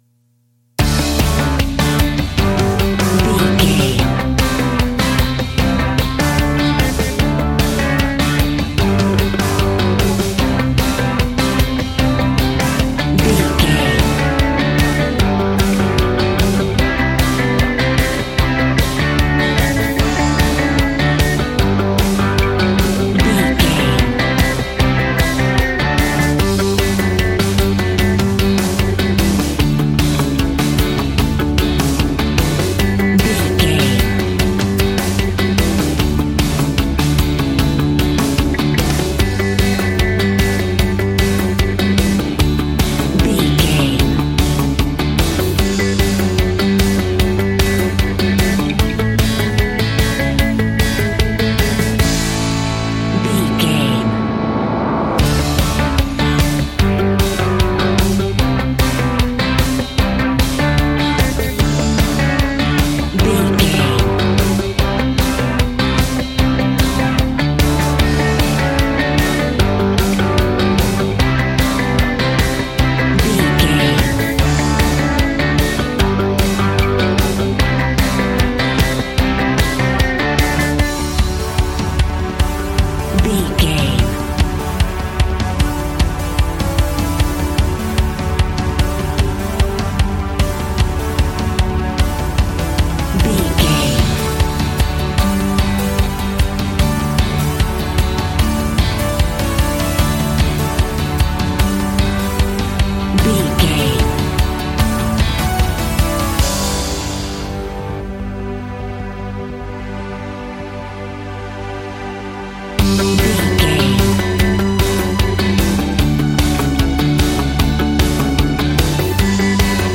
Ionian/Major
groovy
powerful
fun
organ
drums
bass guitar
electric guitar
piano